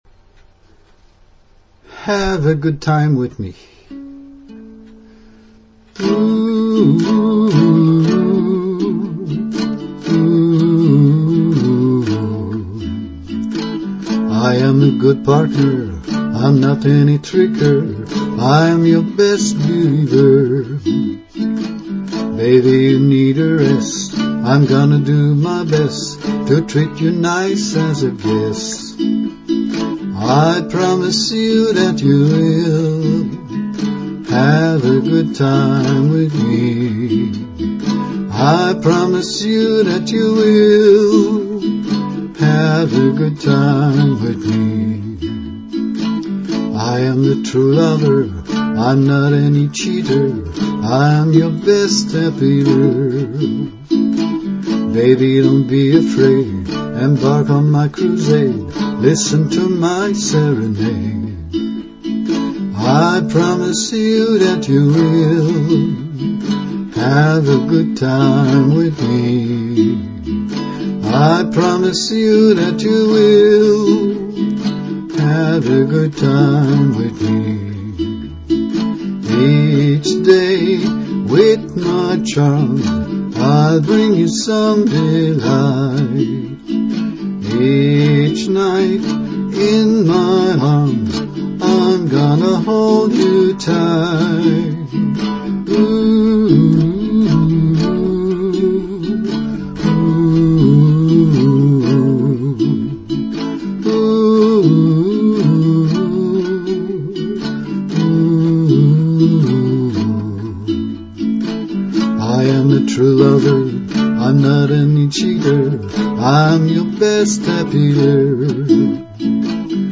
Here is one of my songs.Rough demo: My voice + ukulele